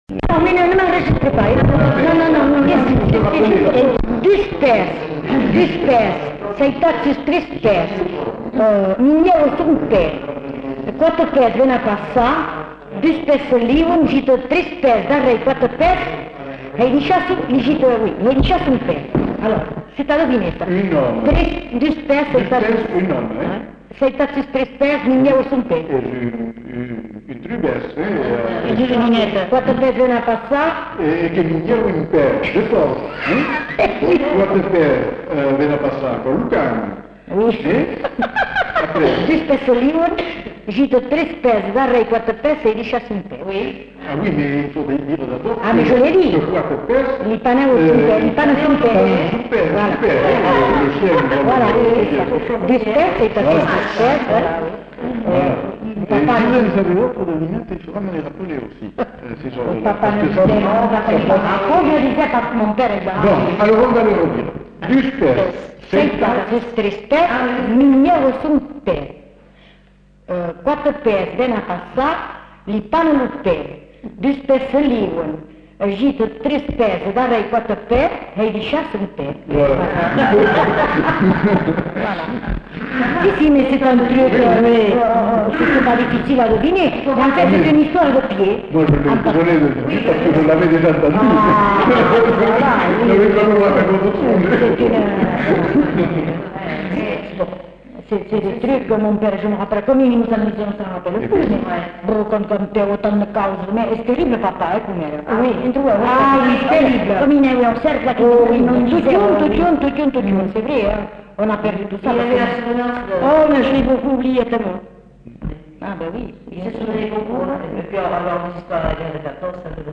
Lieu : Cazalis
Effectif : 1
Type de voix : voix de femme
Production du son : récité
Classification : devinette-énigme